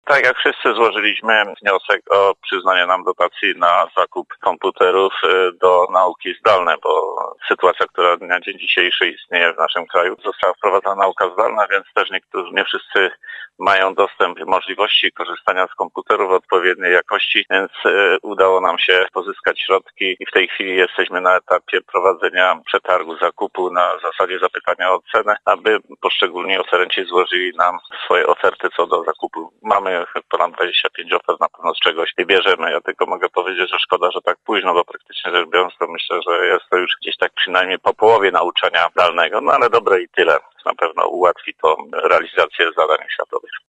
– mówił wicestarosta powiatu wieruszowskiego, Stefan Pietras.